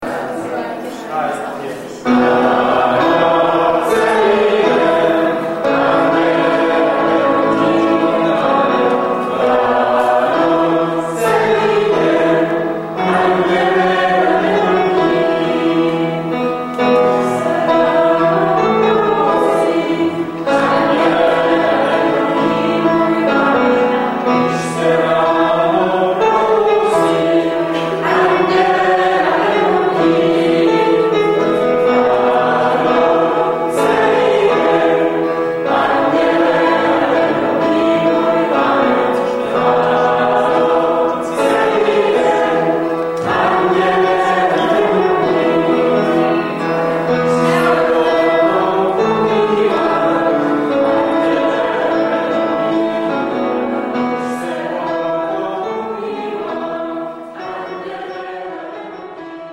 Im Archiv der Osterbegegnungen haben wir ein paar JA-Klassiker gefunden.